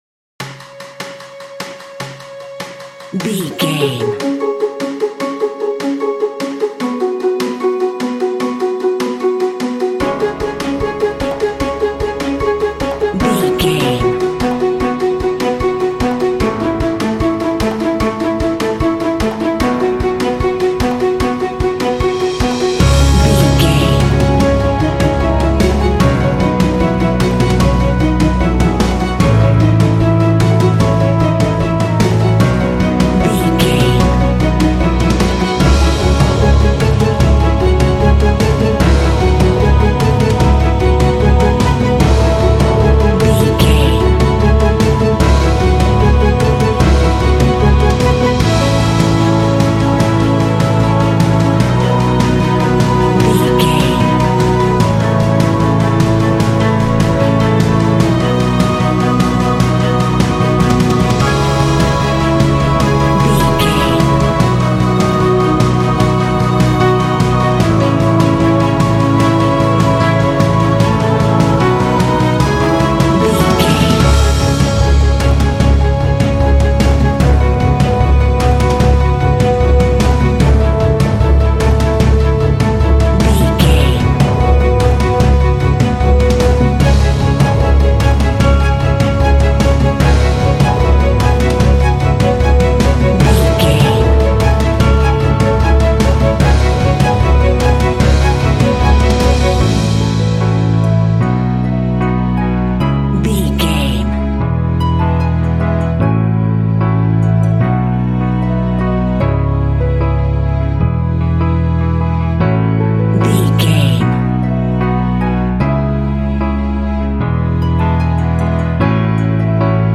Aeolian/Minor
C#
motivational
driving
dramatic
drums
percussion
strings
piano
harp
brass
horns
cinematic
symphonic rock